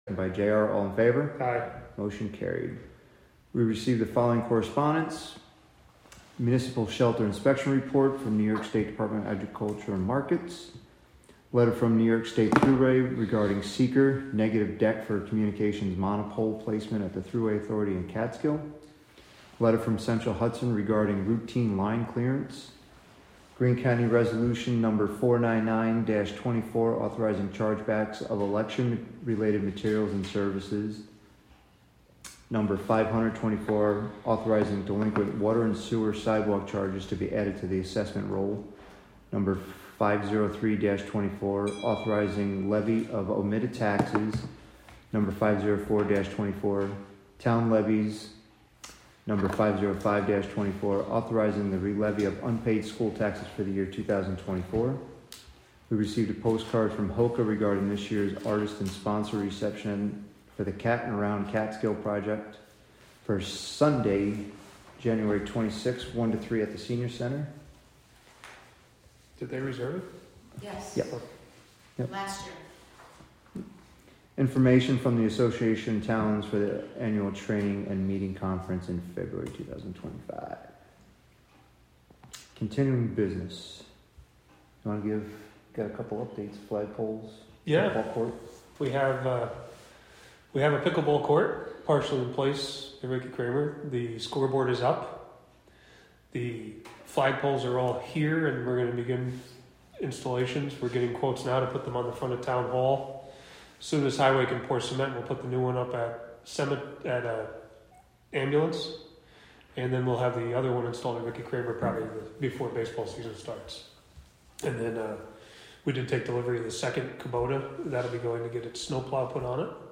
Live from the Town of Catskill: January 7, 2025 Catskill Town Board Meeting (Audio)